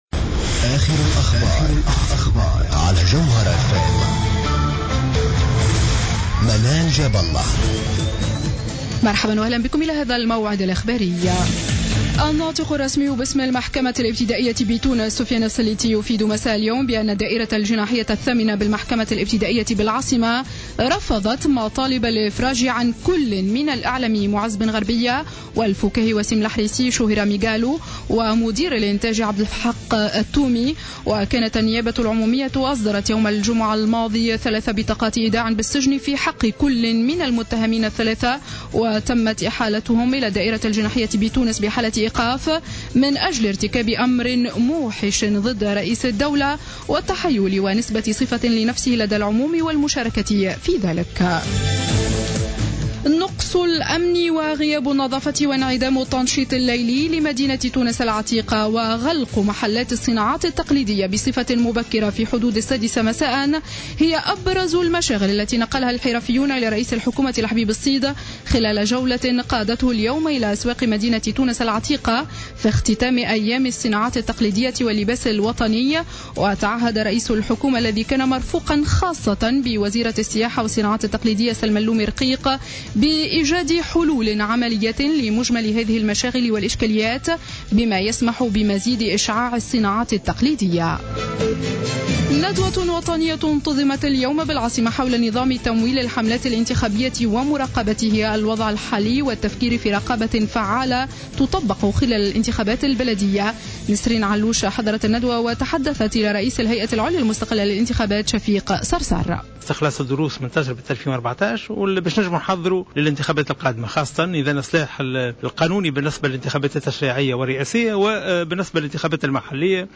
نشرة أخبار السابعة مساء ليوم الاثنين 16-03-15